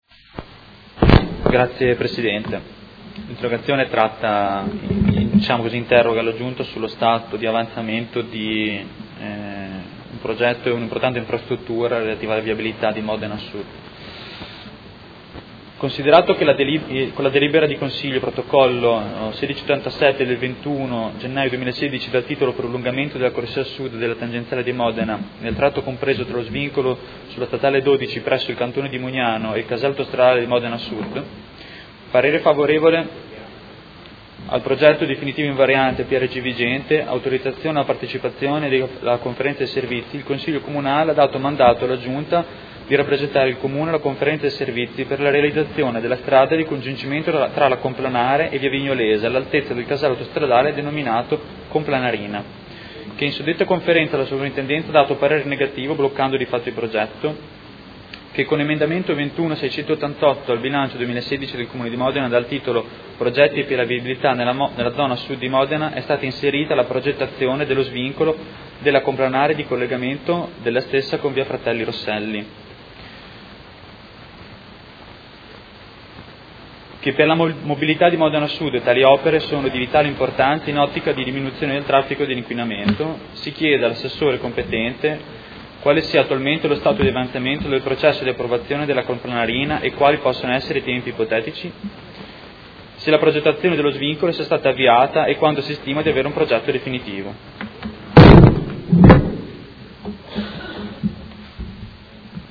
Seduta del 16/02/2017. Interrogazione dei Consiglieri Lenzini e Poggi (P.D.) avente per oggetto: Viabilità zona Sud di Modena